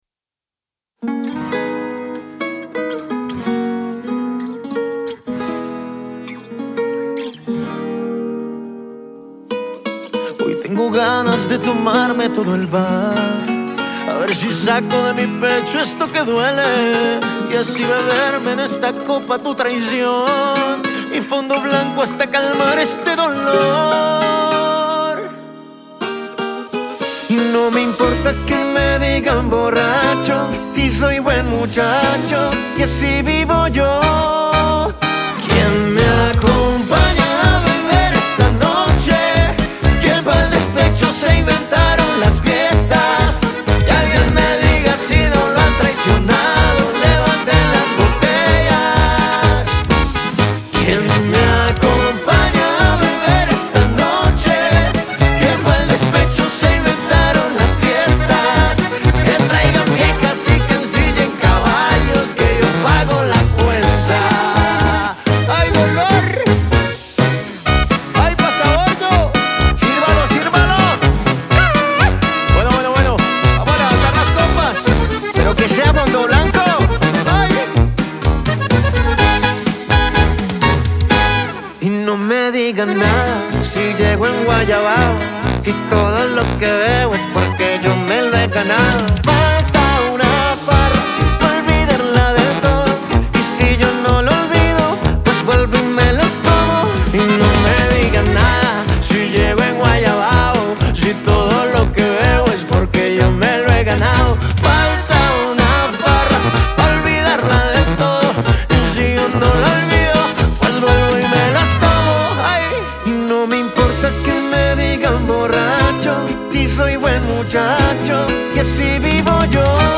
El dúo colombiano